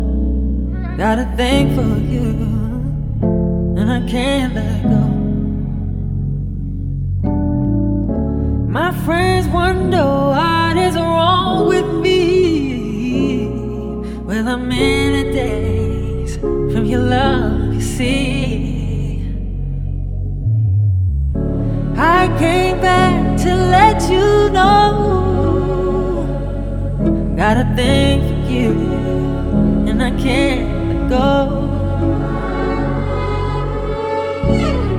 Жанр: R&B / Соул